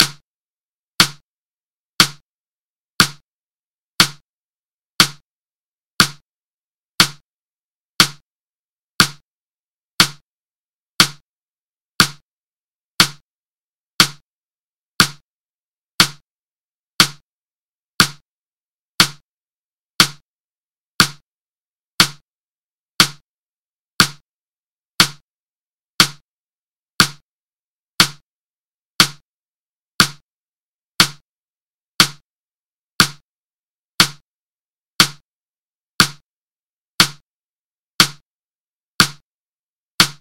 Metronome at 60bpm
60bpm.mp3